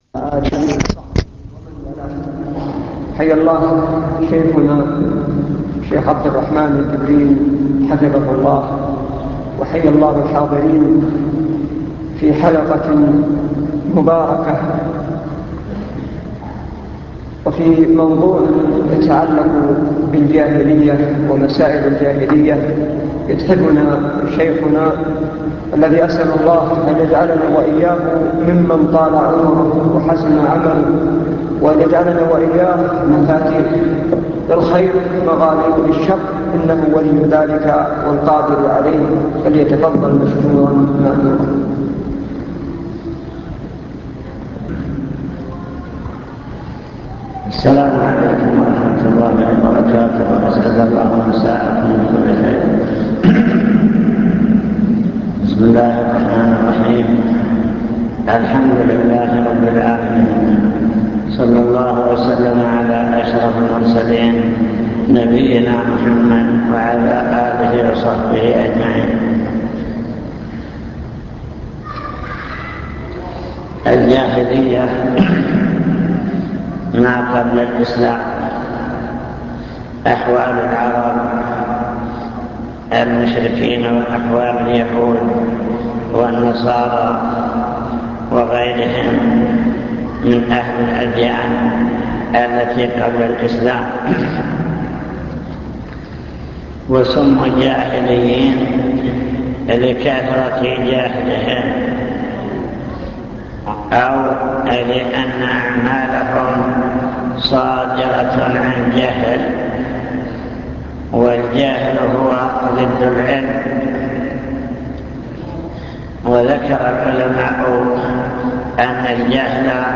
المكتبة الصوتية  تسجيلات - محاضرات ودروس  مسائل الجاهلية